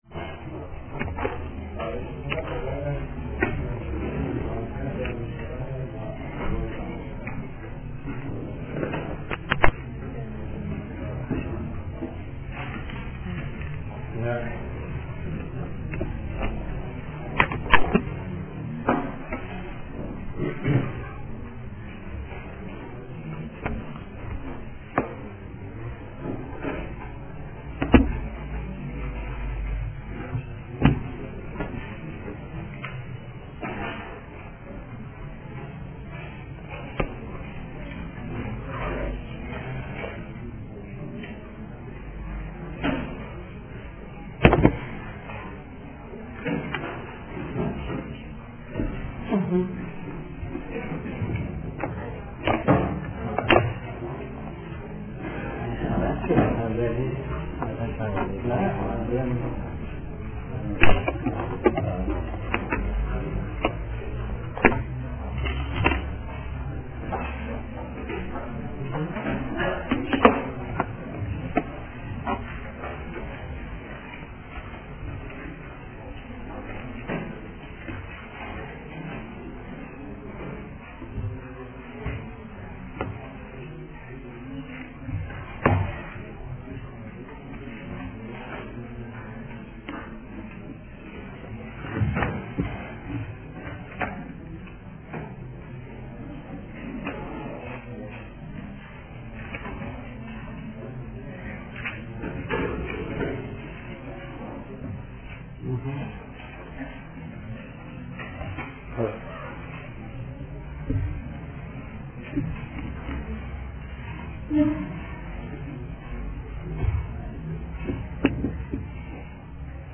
صوت/ درس‌گفتارهای ماکیاوللی(۲)
فرهنگ امروز: فایل حاضر قسمت دوم درس‌گفتار های ماکیاوللی است که «سیدجواد طباطبایی» سال‌ها پیش آن را تدریس کرده است.